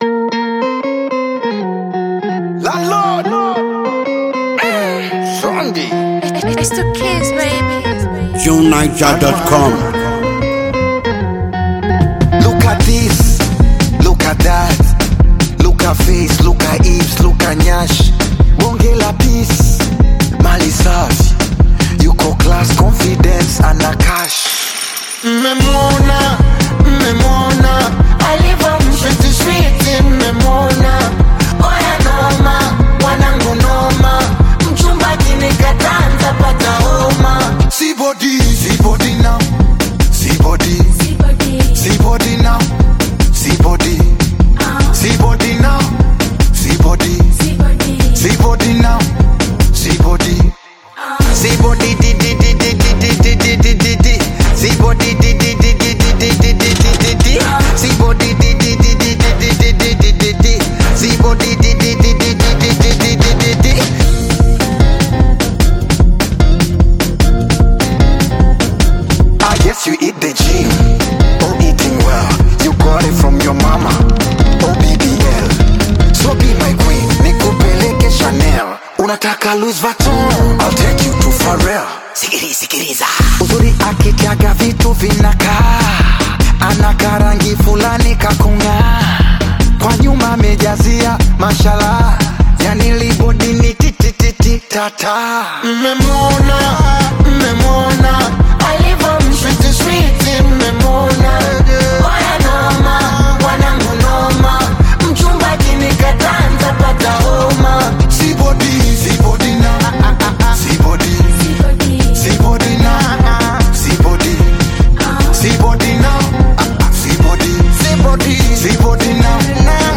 enticing and well-chanted song